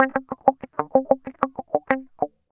Track 13 - Clean Guitar Wah 06.wav